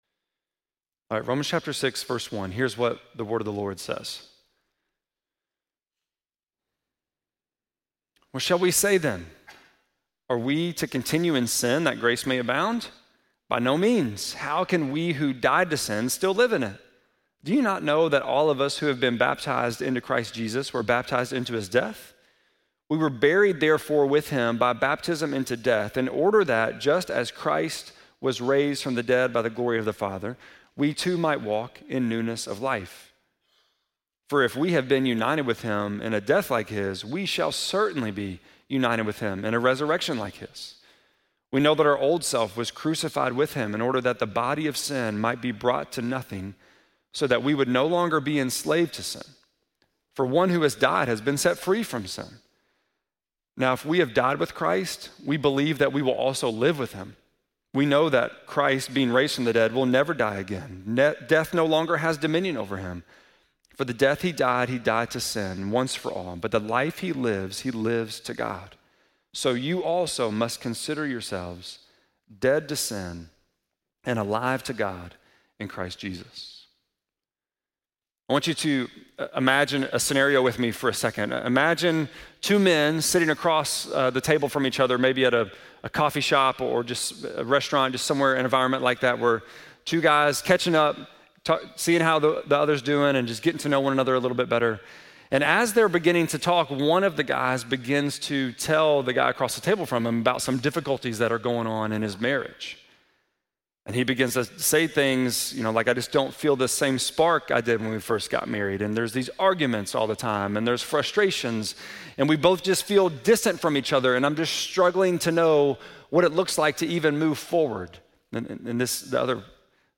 8.17-sermon.mp3